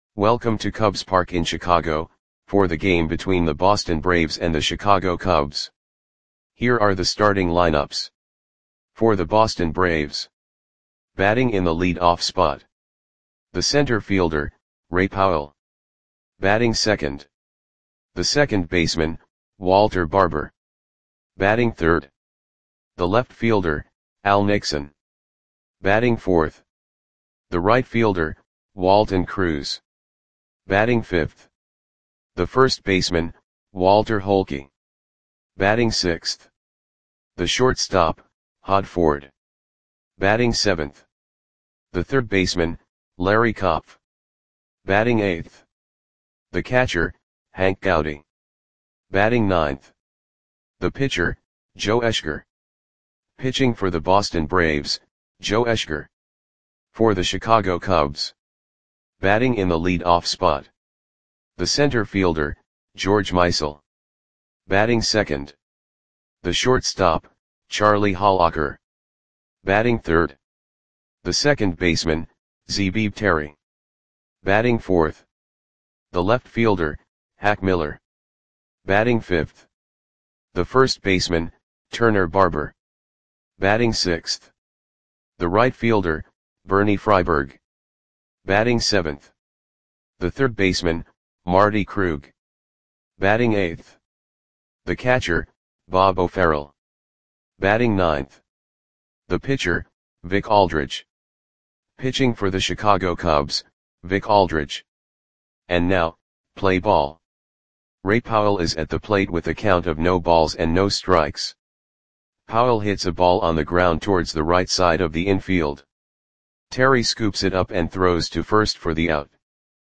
Audio Play-by-Play for Chicago Cubs on July 9, 1922
Click the button below to listen to the audio play-by-play.